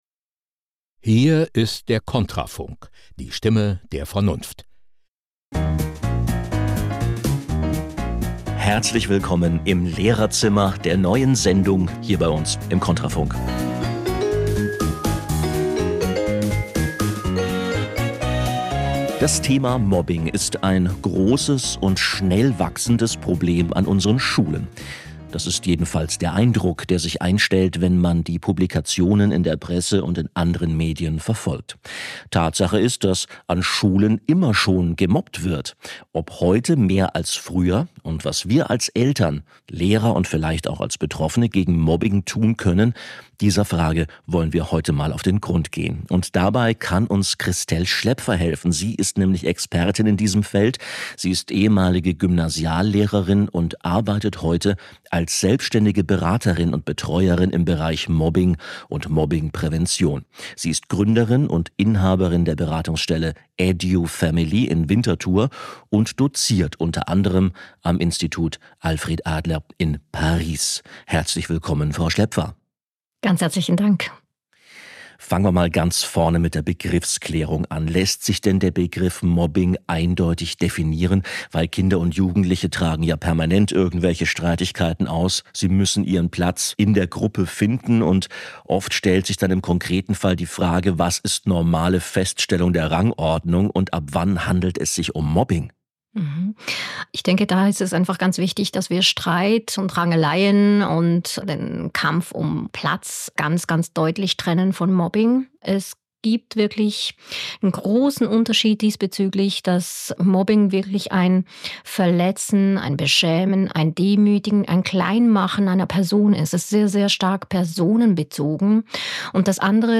Radio Interview zum Thema Mobbing